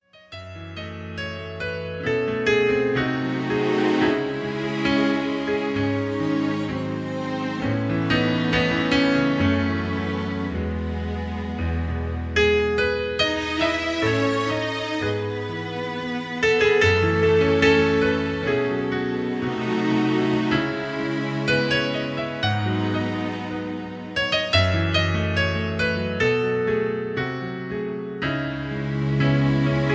karaoke mp3 tracks